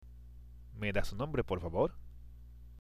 （メダス　ノンブレ　ポルファボール？）